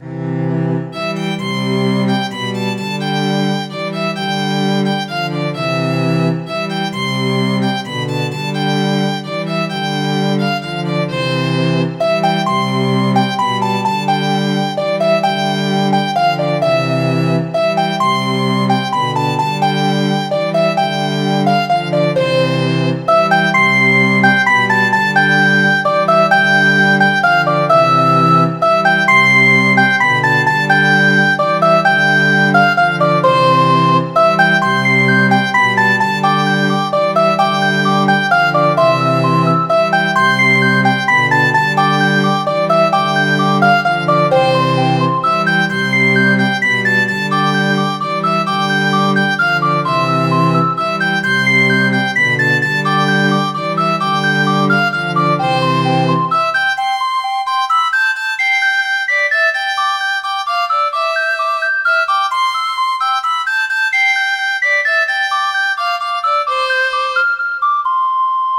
Midi File, Lyrics and Information to Tarry Trousers